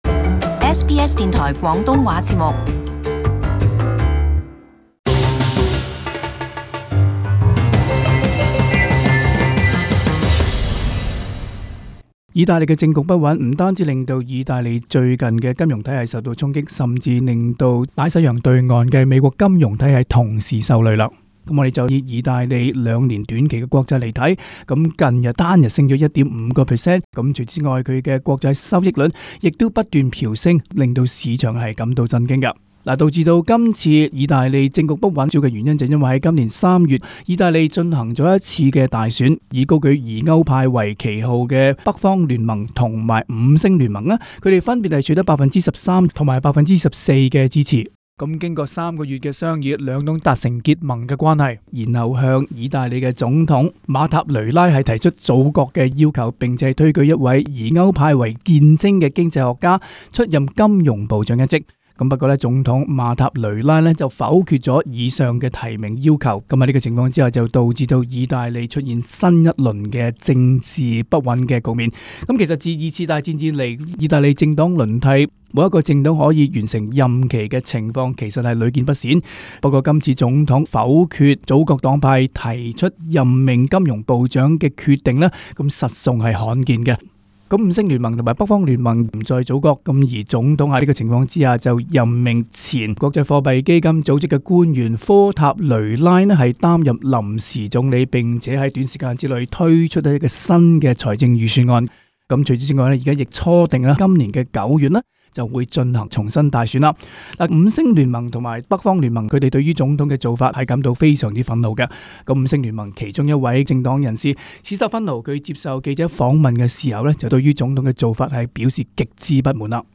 【時事報導】意大利政局發展對歐盟一體性構成威脅